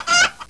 p_damage.wav